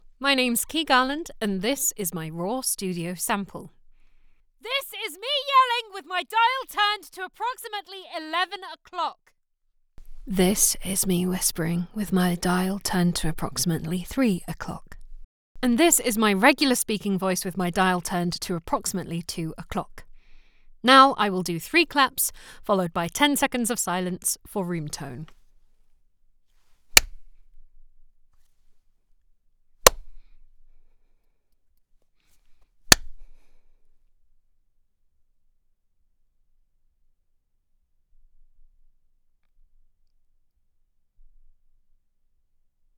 Female
English (British)
Yng Adult (18-29), Adult (30-50)
Studio Quality Sample
All our voice actors have professional broadcast quality recording studios. They record using professional microphones, not using cheap plastic microphones like you'll get at other websites.